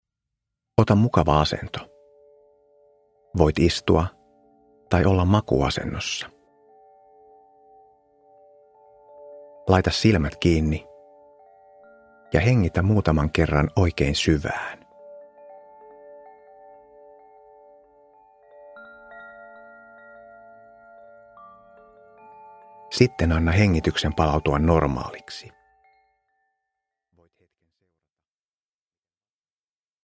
Kehorentoutusmeditaatio 5 min – Ljudbok – Laddas ner